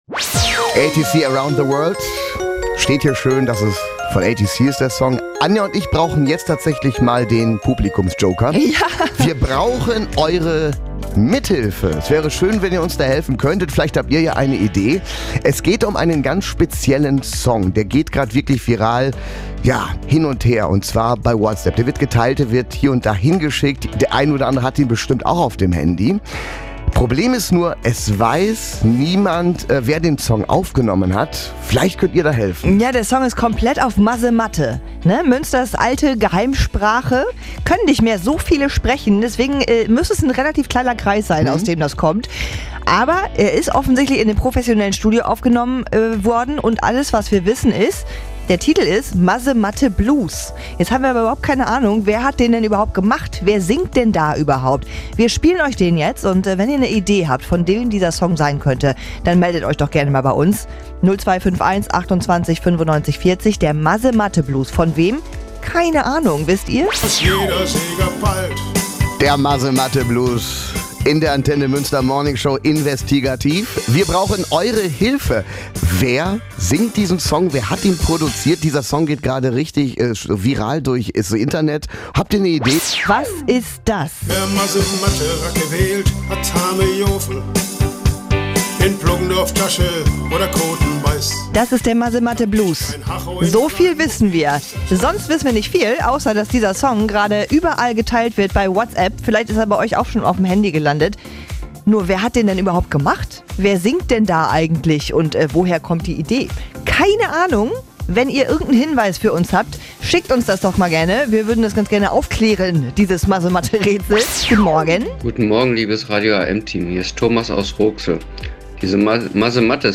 Die komplette Suche im Radio nach dem Interpreten des Masematte Blues inklusive Auflösung könnt ihr auch nochmal nachhören.